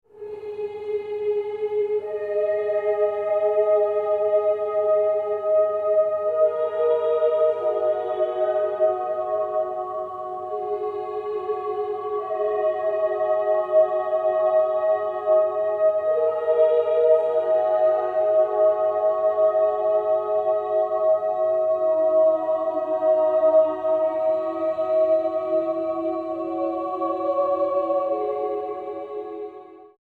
Open air performance